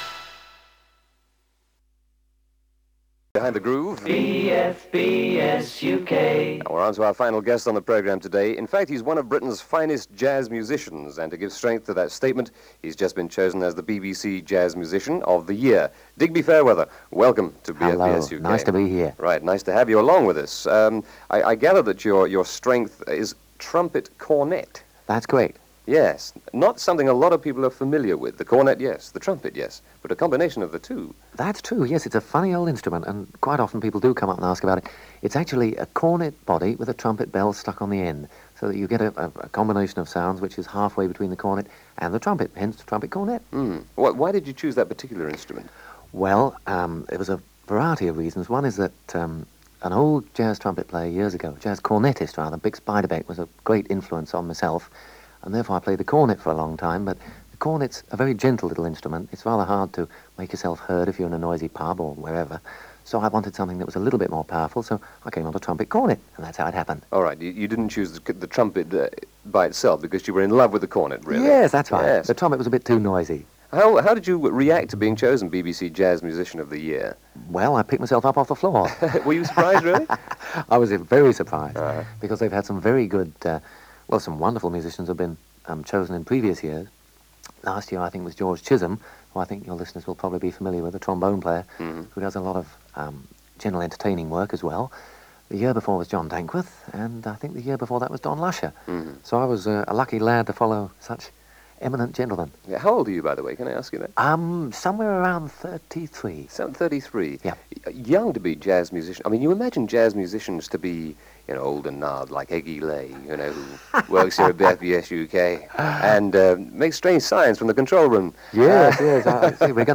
BFBS Interview From 1979